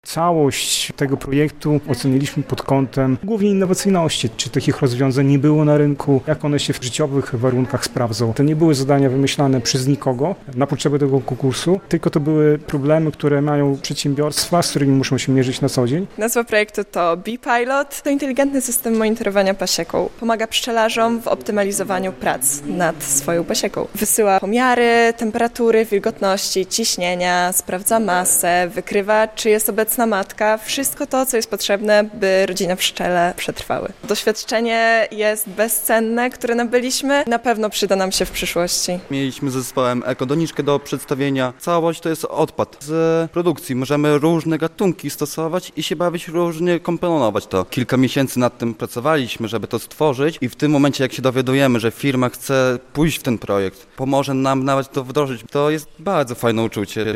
„Wschodzący Innowatorzy” na Politechnice Białostockiej - relacja